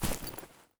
48d440e14c Divergent / mods / Soundscape Overhaul / gamedata / sounds / material / actor / step / earth3.ogg 29 KiB (Stored with Git LFS) Raw History